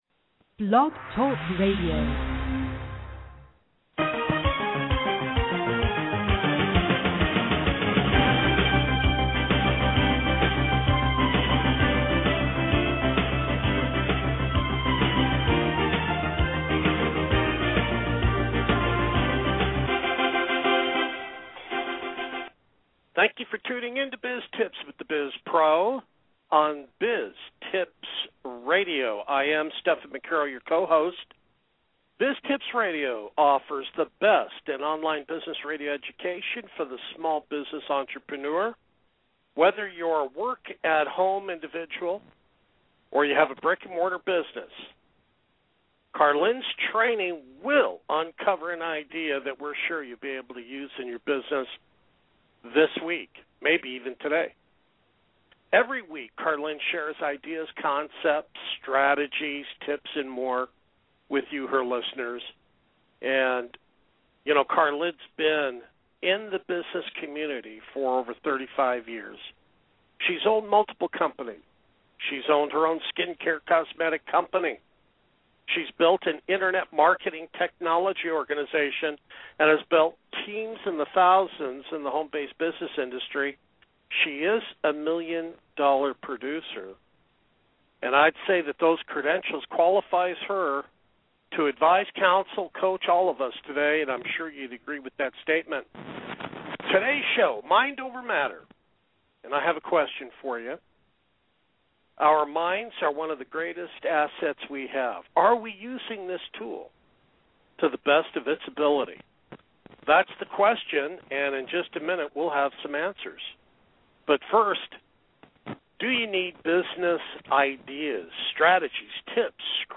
Our minds are one of our greatest assets. Are we using this tool to the best of its ability? Listen to my radio show replay and see why your mind matters!